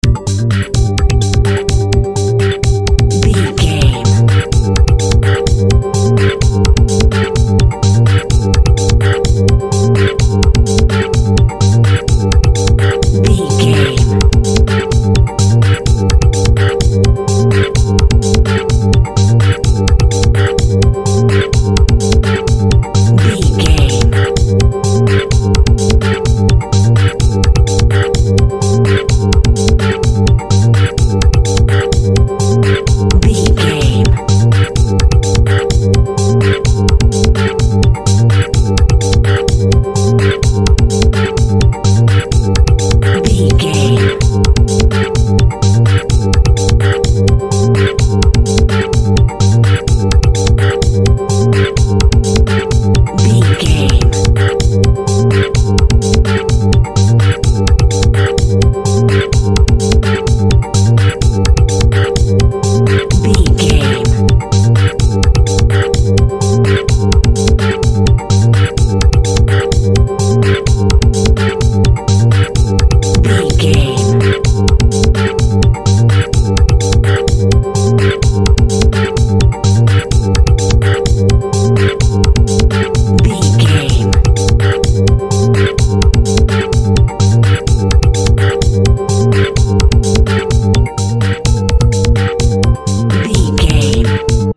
Aeolian/Minor
futuristic
hypnotic
dreamy
groovy
Drum and bass
break beat
electronic
sub bass
synth lead
synth bass
synth drums